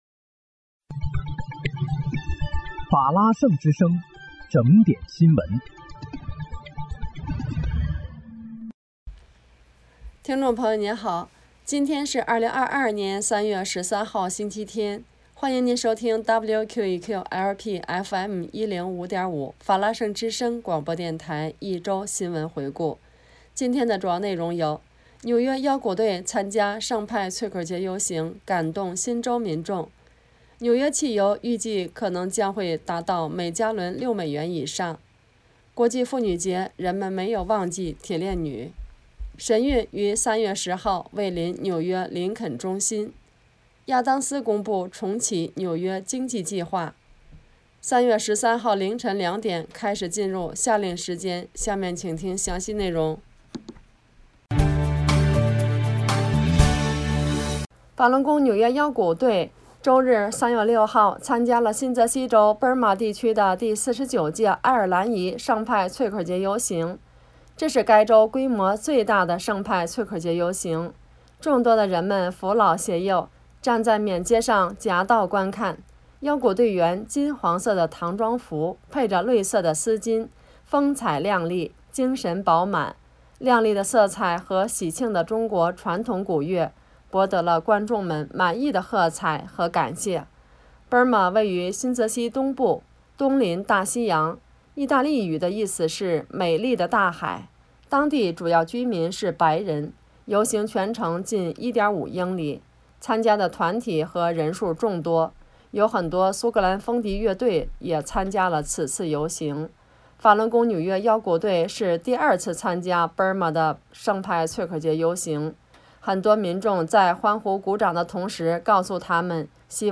3月13日（星期日）一周新闻回顾